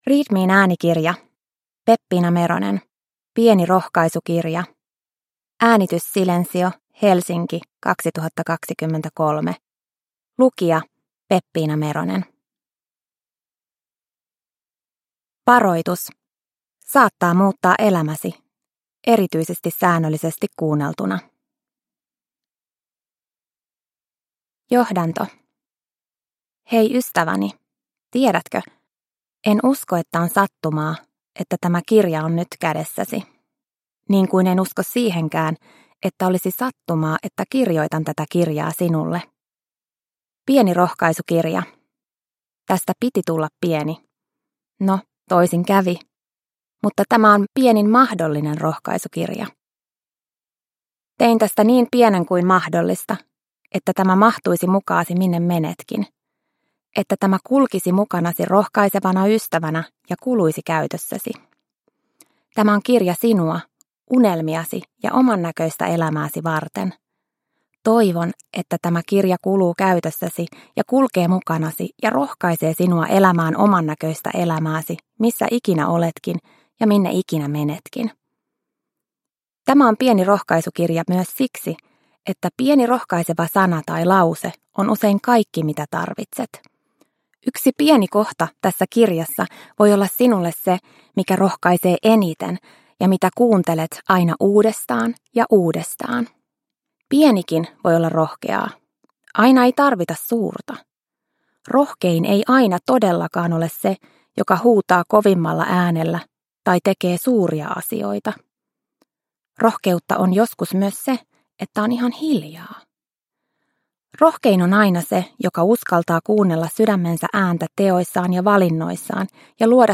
Pieni rohkaisukirja – Ljudbok